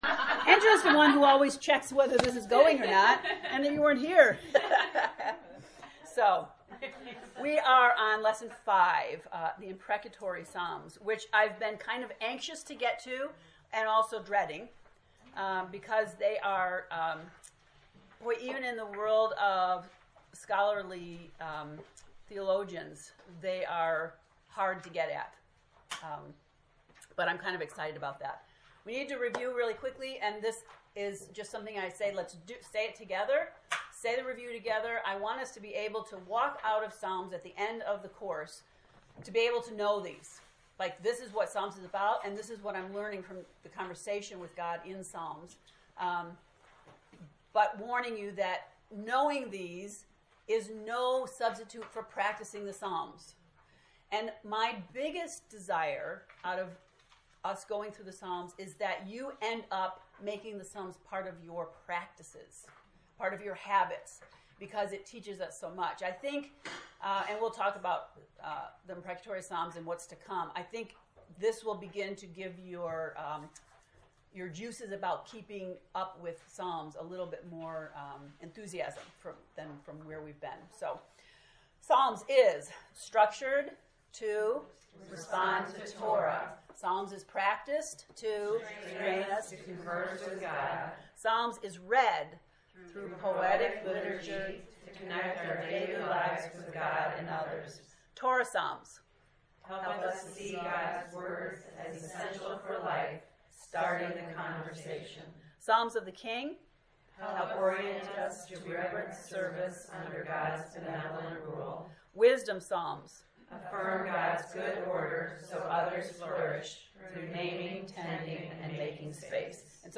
To listen to lecture 5, “Imprecatory Psalms,” click below: